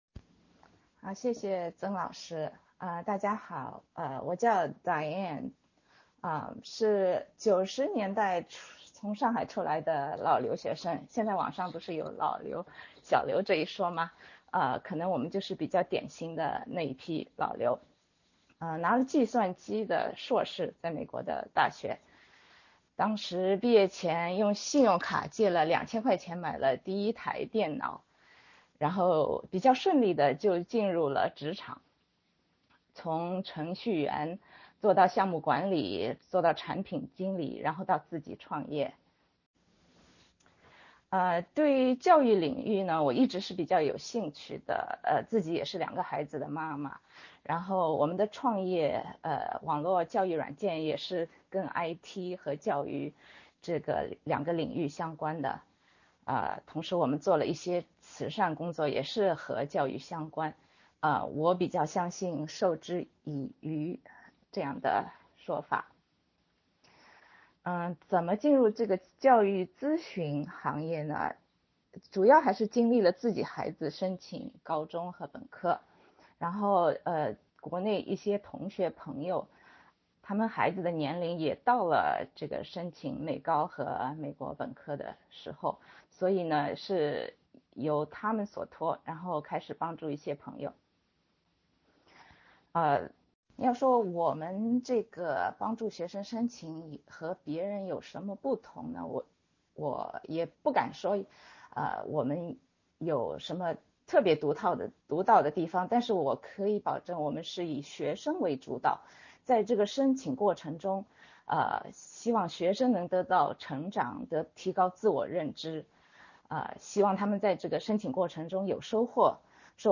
芝加哥国际教育美本申请公益讲座